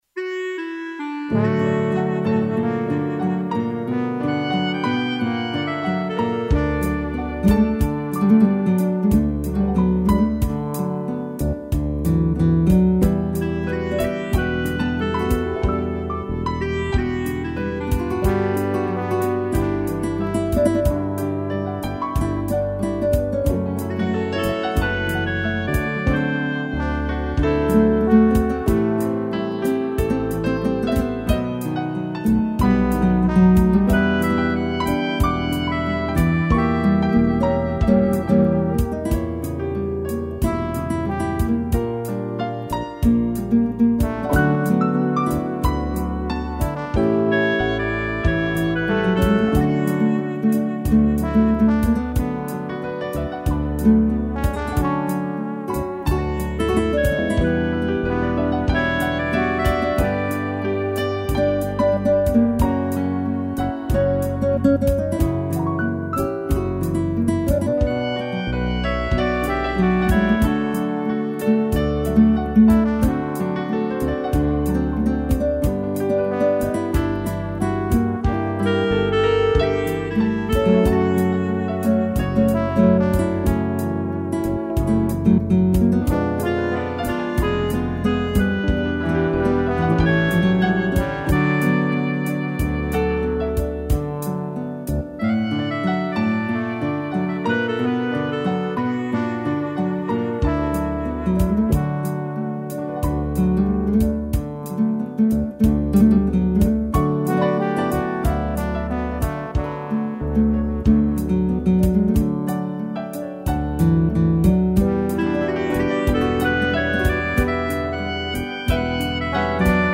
piano, clarineta e trombone
instrumental